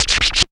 SHORT SCRATC.wav